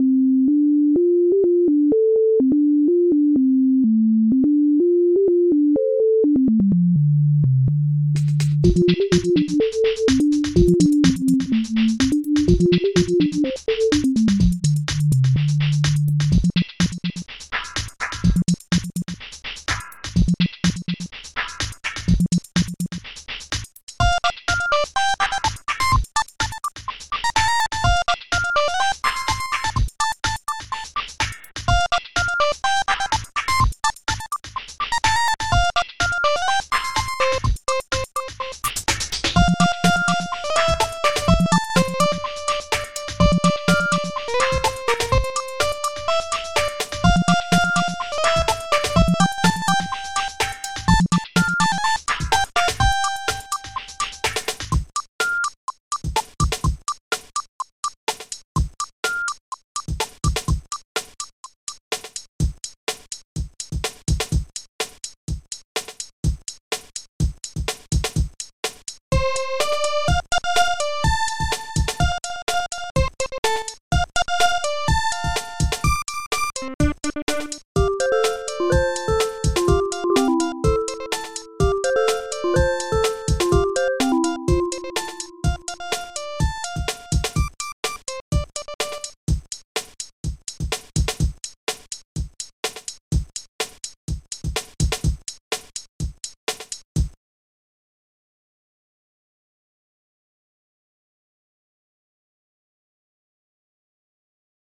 Tout ça vient de fast tracker II, sous Dos, à l'époque ou bill gates n'était pas encore maître du monde, à savoir avant 1997.
Puis on arrive au tube au son chip qui tue,